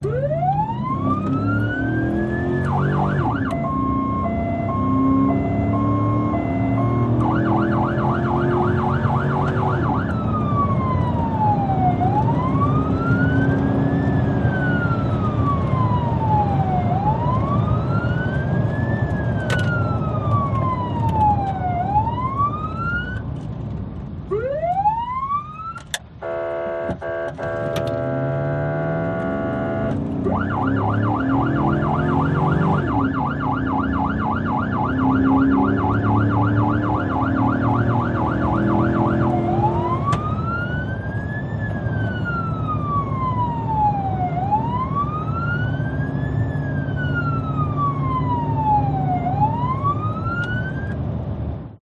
Звуки полицейской машины
Звук погони полиции в салоне на скорости